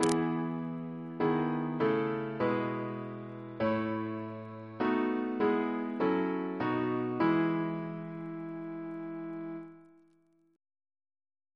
Single chant in E Composer: Gerard F. Cobb (1838-1904) Reference psalters: ACB: 134